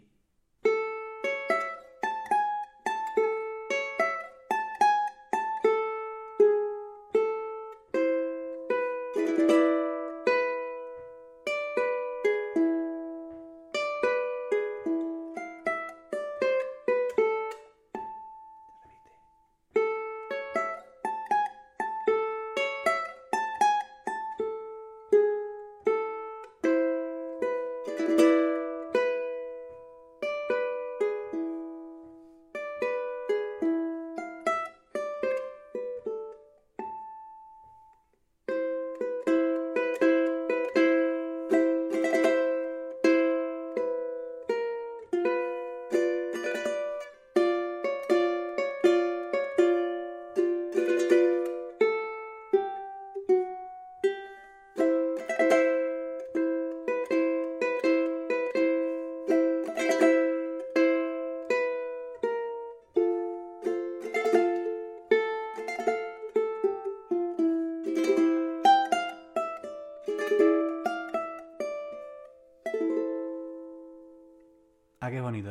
Mazurcas para TIMPLE
Escrita en compás ternario (3/4 o 3/8), se caracteriza por la utilización del ritmo «corchea con puntillo y semicorchea».